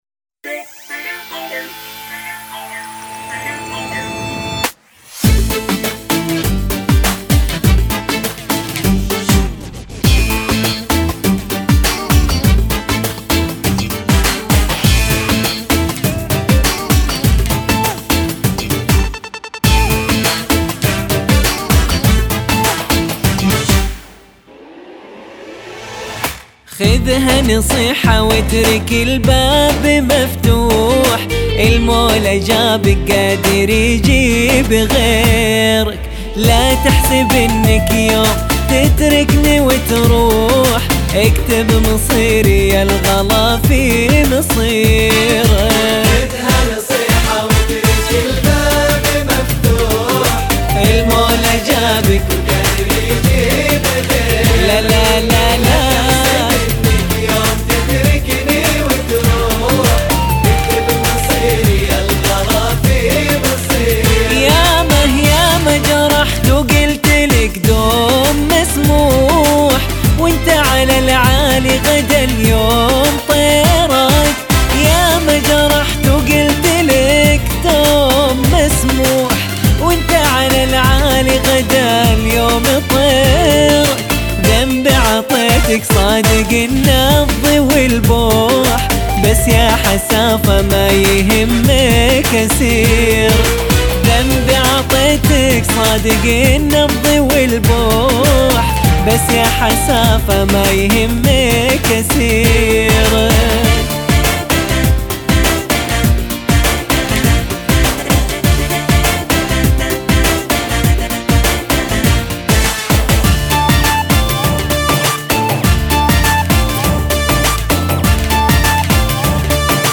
كورال